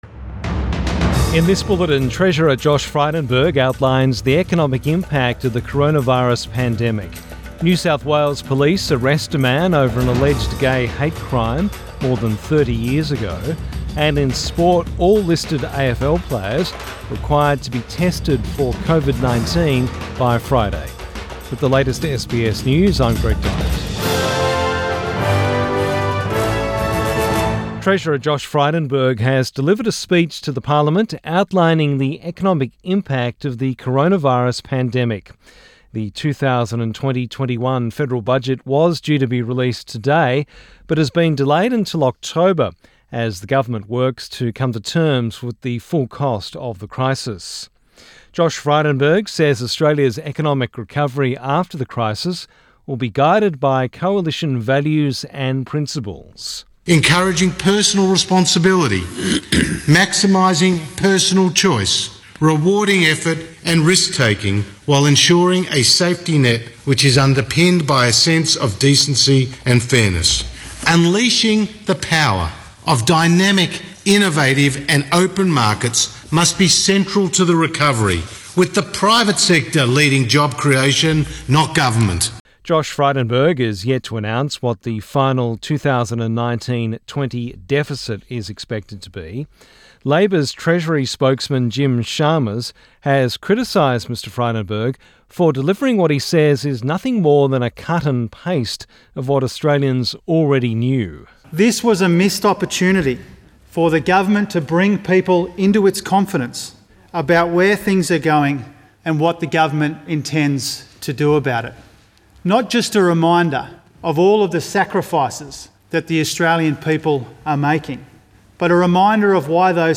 PM bulletin 12 May 2020